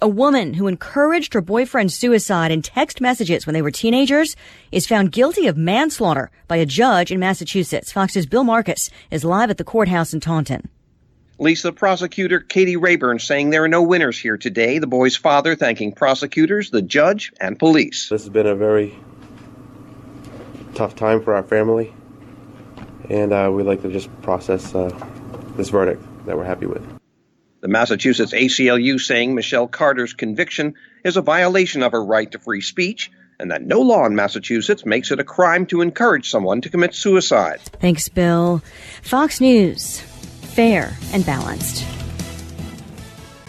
2PM – LIVE –
2pm-live.mp3